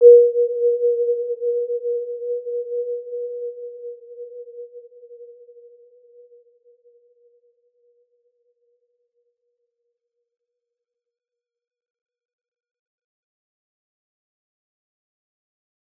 Warm-Bounce-B4-p.wav